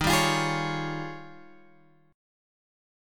D# 11th